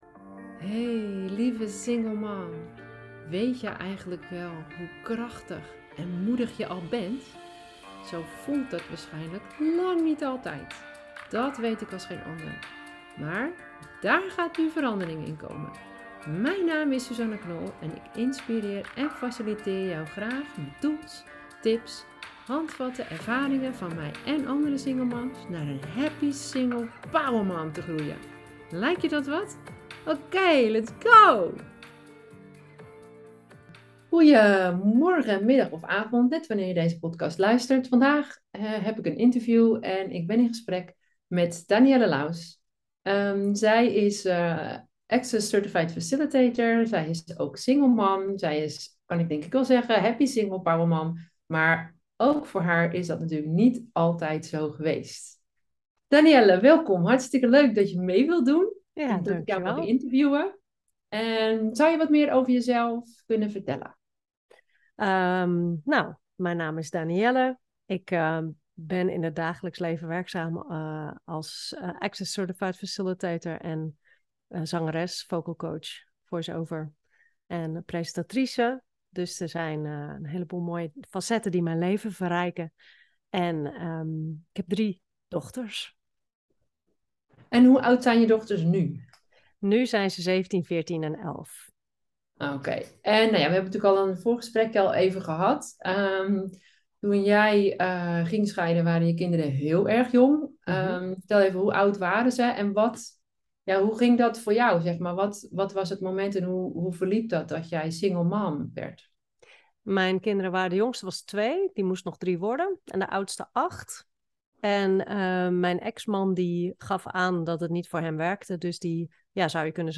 Interview
Een prachtig gesprek dat mij inspireerde en ontroerde.